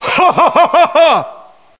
tl_haha.mp3